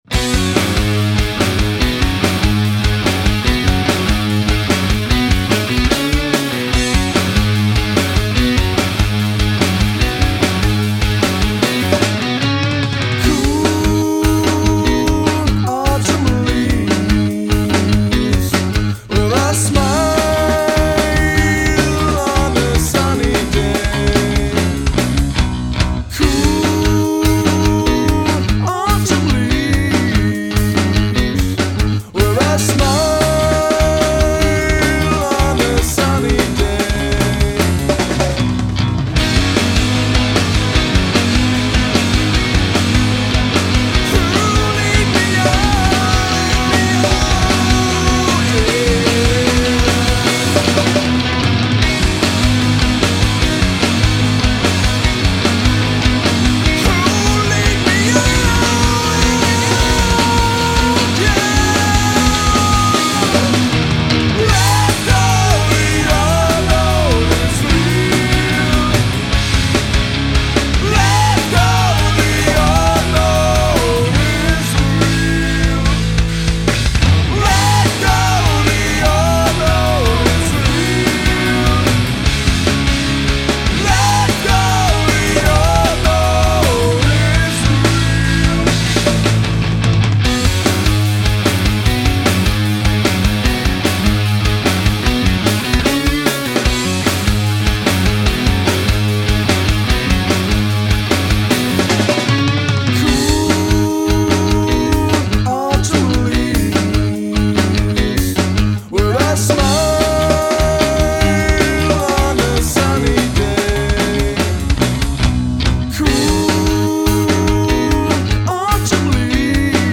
Ein 70er Jahre Riff Gewitter.